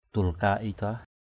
/d̪ul-ka – i-dah/ (d.) tháng 11 Hồi giáo = 11ème mois musulman.